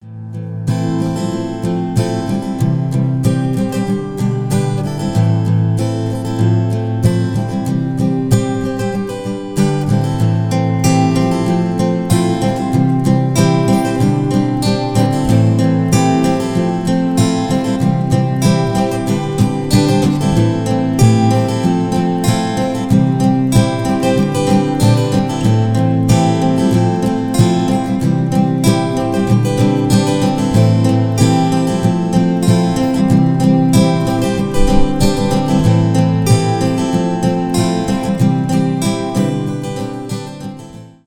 гитара , инструментальные
без слов
альтернатива